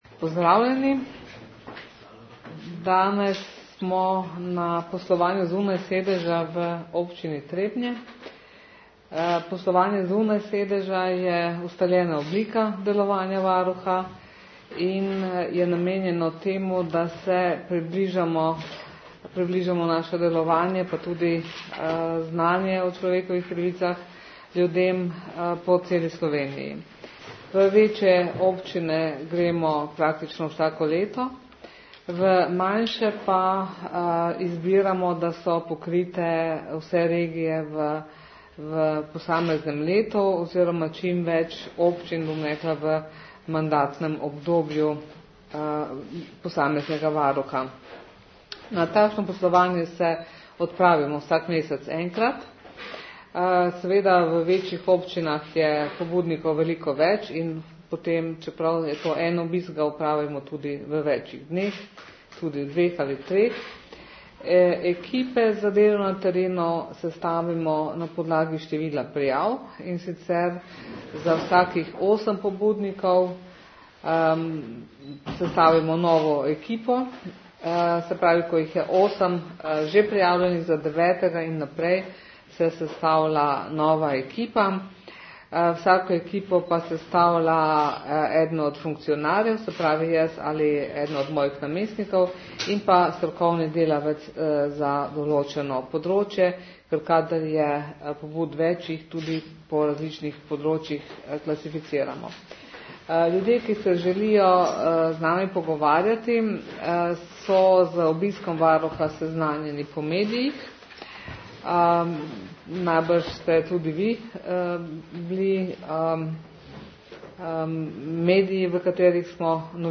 ZVOČNI POSNETEK NOVINARSKE KONFERENCE (MP3)
Po pogovorih je varuhinja človekovih pravic svoje ugotovitve predstavila na krajši novinarski konferenci.